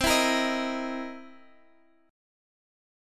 C7#9 Chord
Listen to C7#9 strummed